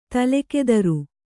♪ tale kedru